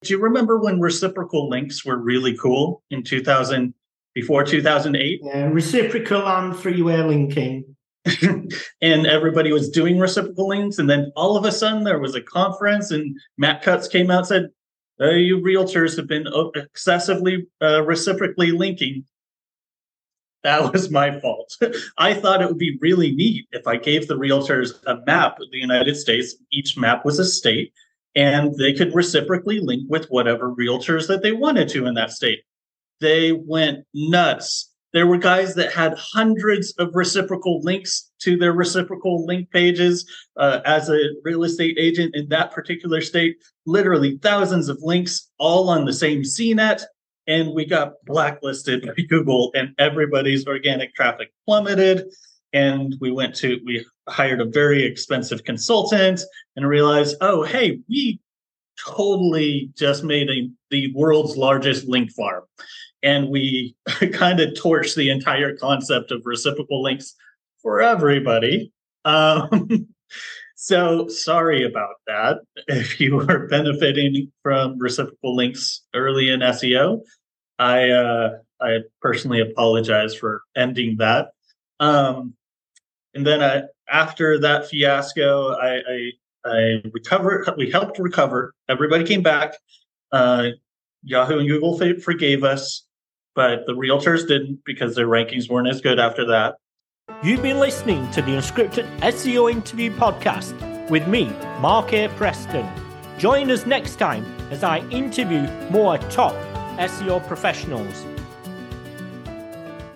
The Unscripted SEO Interview Podcast